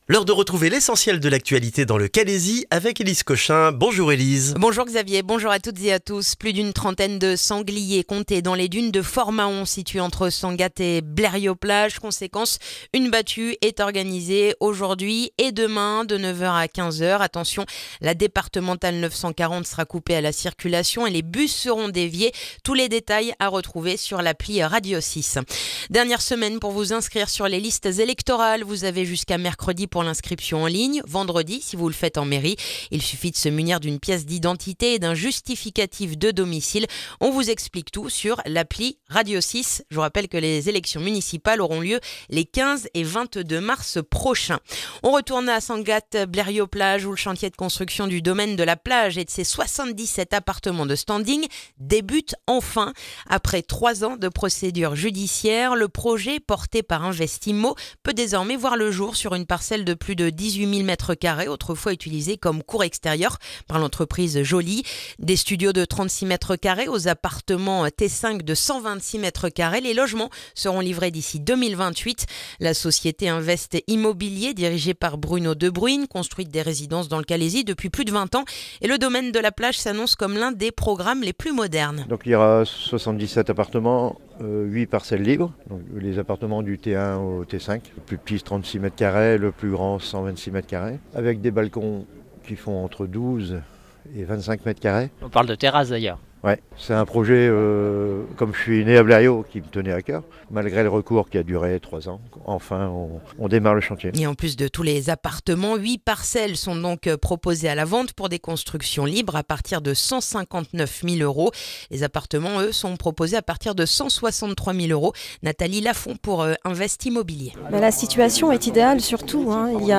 Le journal du lundi 2 février dans le calaisis